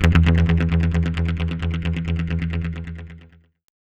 GUITARFX 5-L.wav